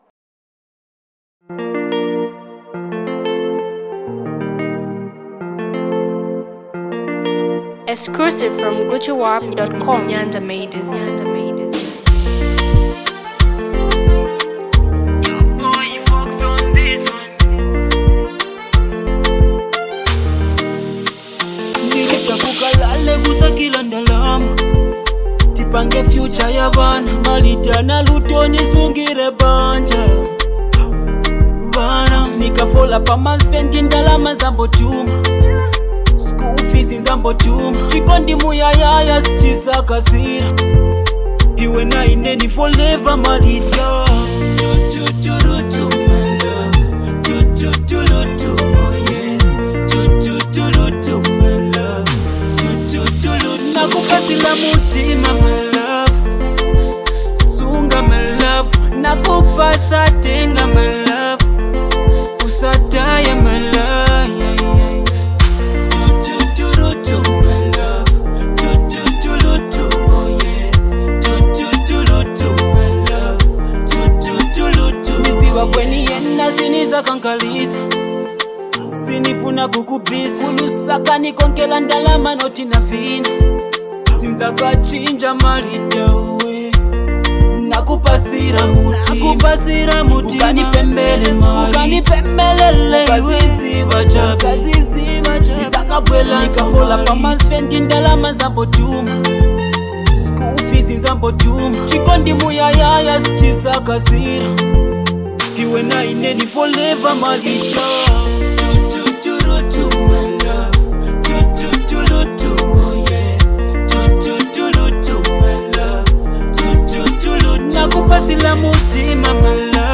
is one of his stunning melodic sound